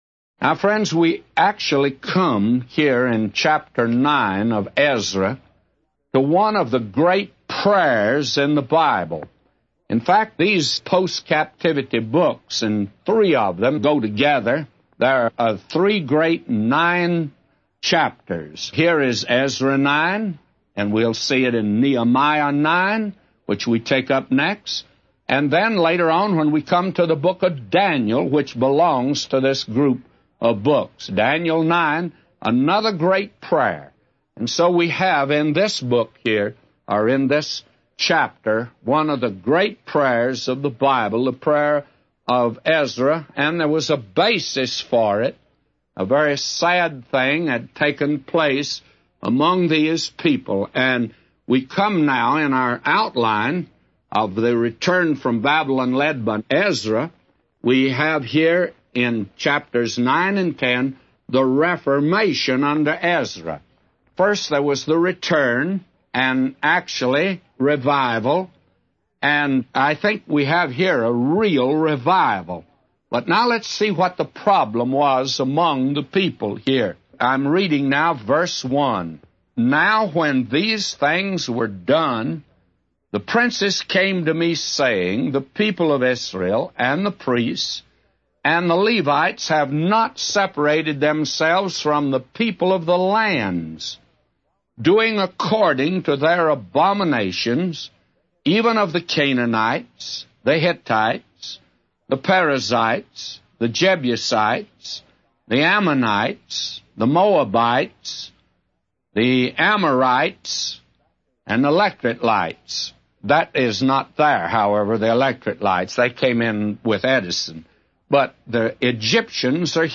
A Commentary By J Vernon MCgee For Ezra 9:1-999